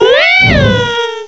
sovereignx/sound/direct_sound_samples/cries/litleo.aif at master